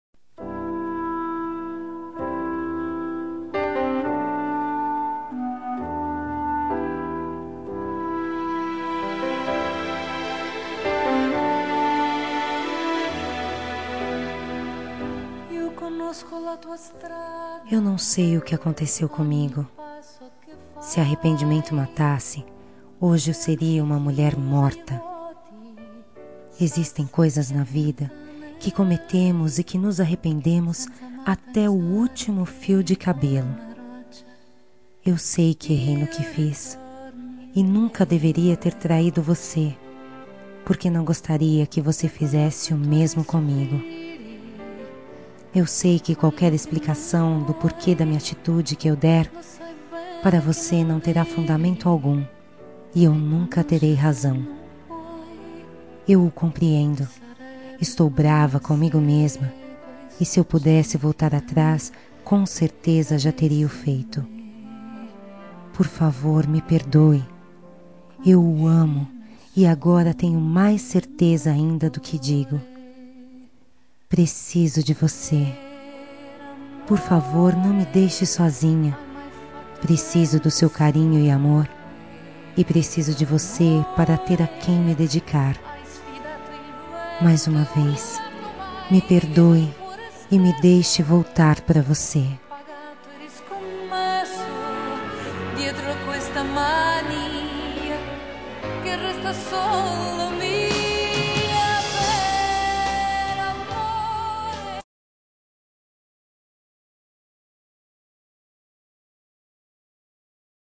Telemensagem de Reconciliação Romântica – Voz Feminina – Cód: 2112 – Traição